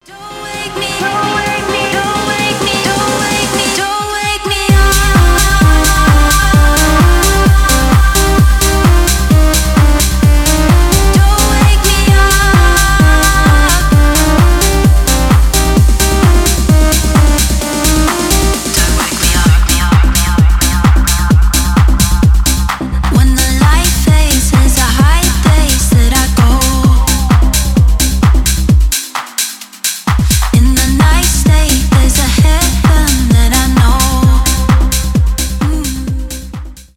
melodic house , tech house